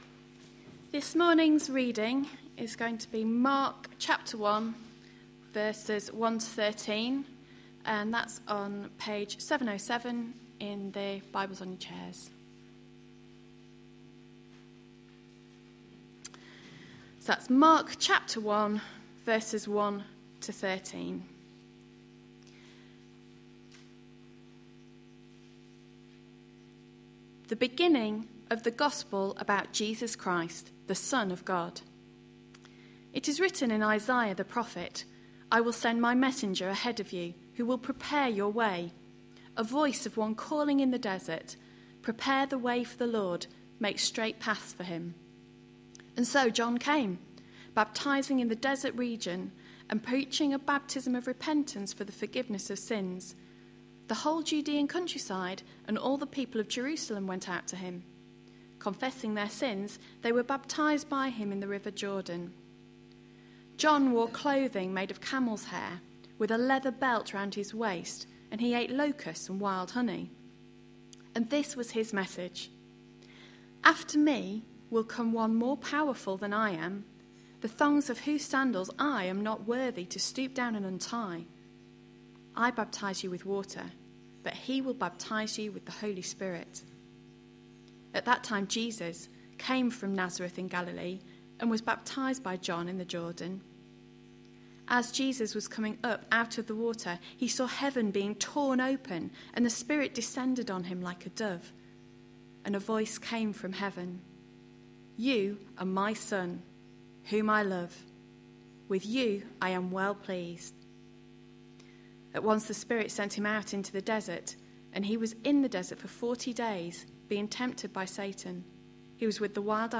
A sermon preached on 2nd October, 2011, as part of our Mark series.